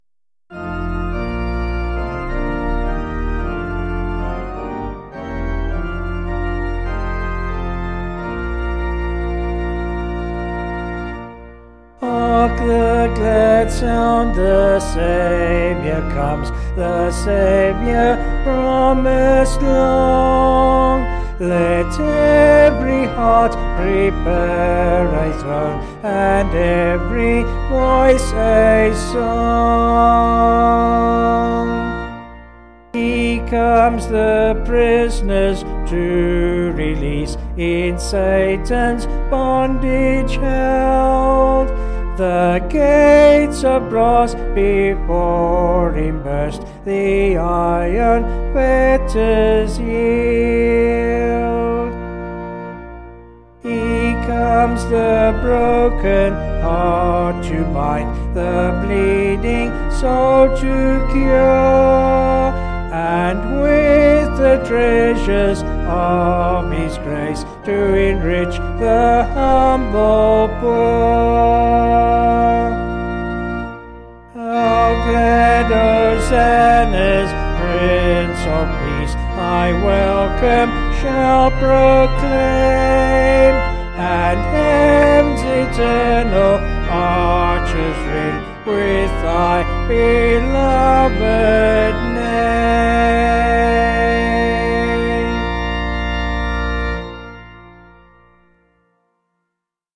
Vocals and Organ   263.9kb Sung Lyrics 1.8mb